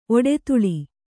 ♪ oḍetuḷi